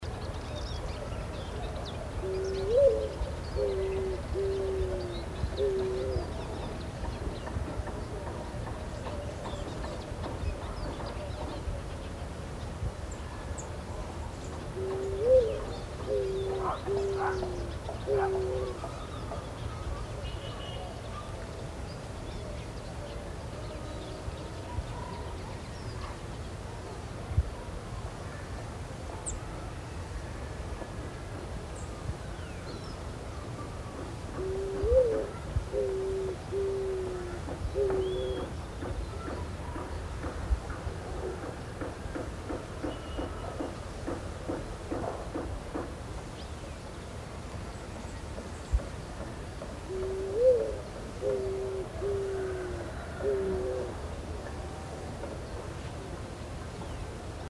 Tortola – Universidad Católica de Temuco
Tortola-Zenaida-auriculata.mp3